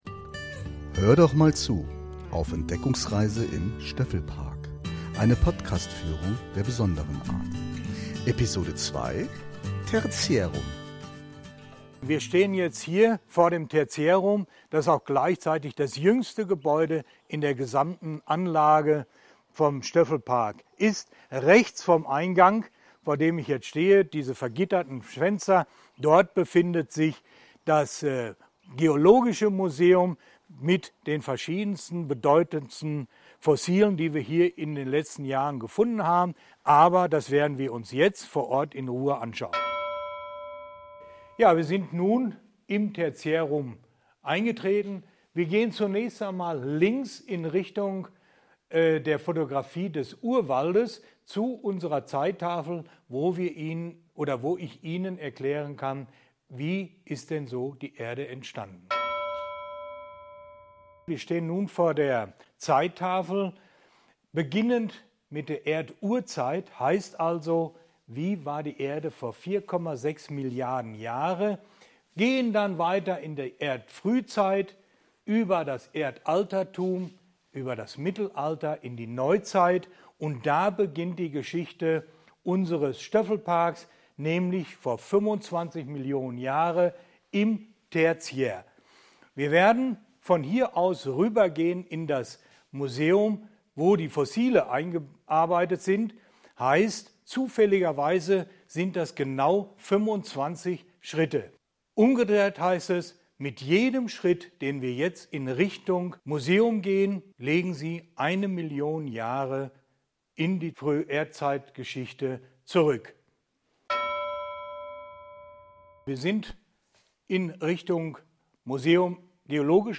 EINE PODCASTFÜHRUNG DURCH DAS TERTIÄRUM IM STÖFFEL-PARK